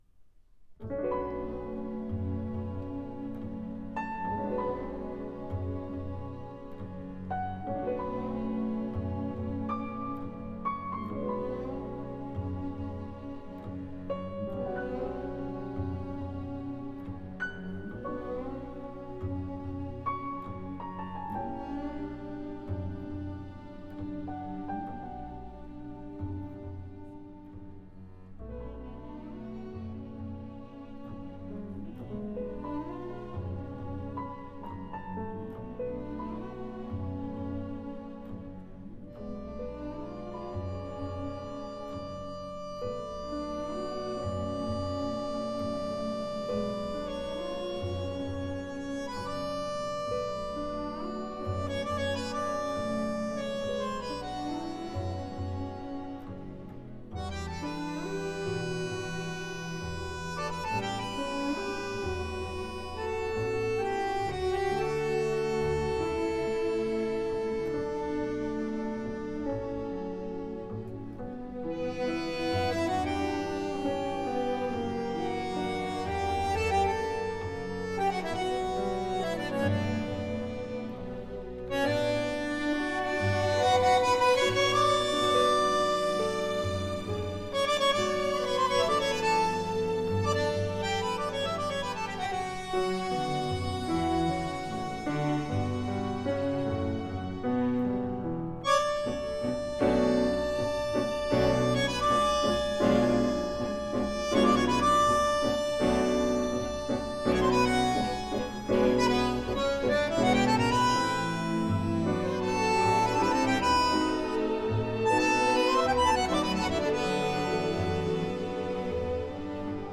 Genre: Tango
Recorded at MCO Hilversum, The Netherlands, April 2002.